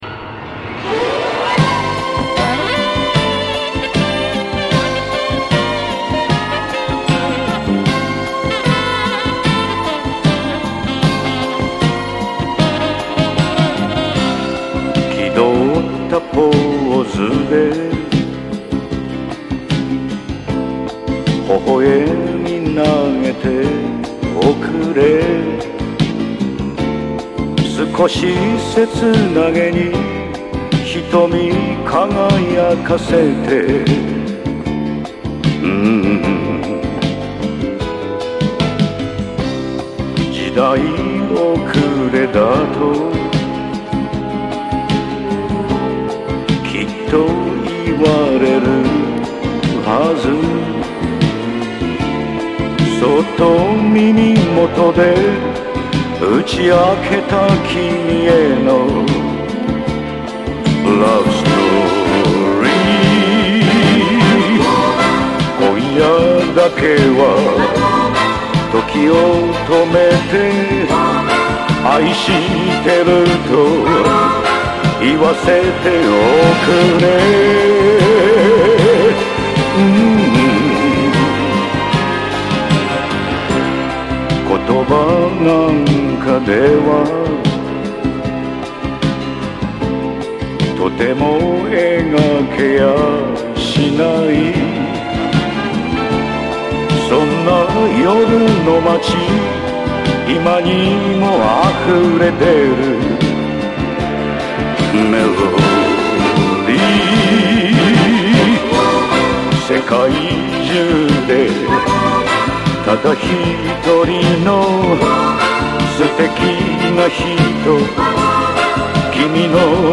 ムード&ゴージャスさ満点の名作
声も渋い！